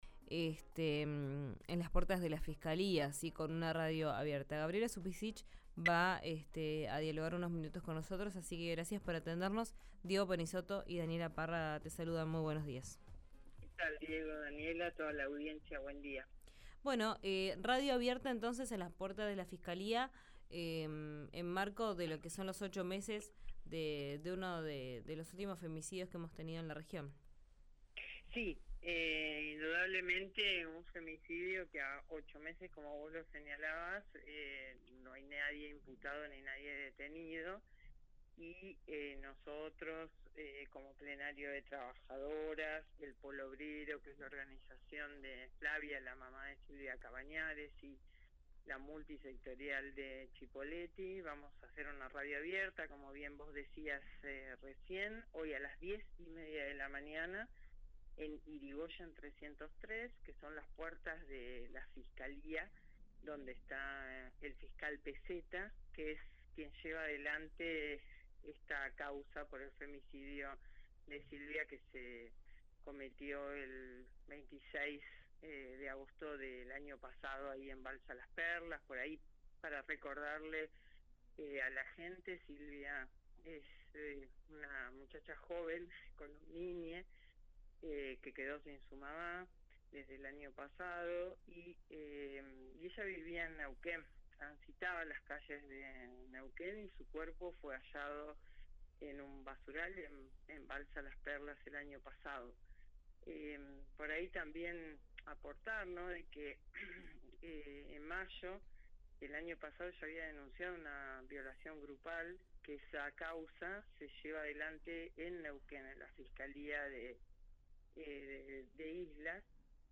en RÍO NEGRO RADIO: